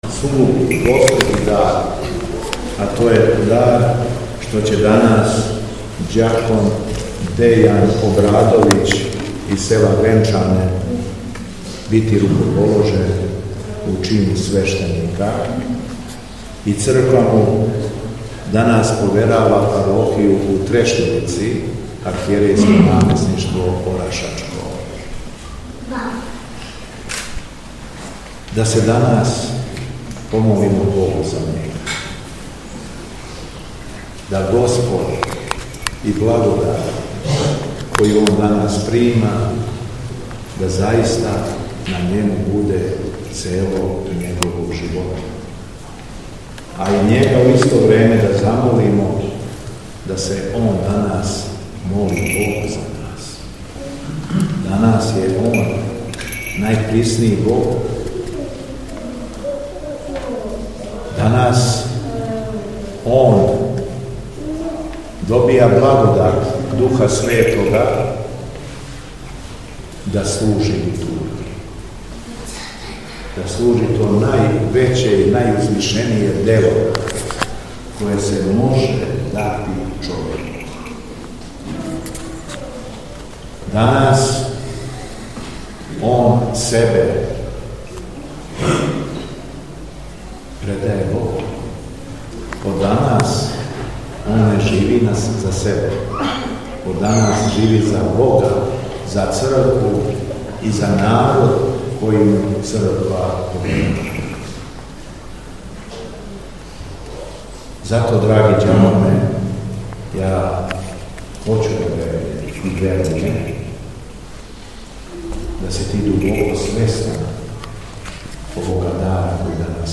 Духовна поука Његовог Високопреосвештенства Митрополита шумадијског г. Јована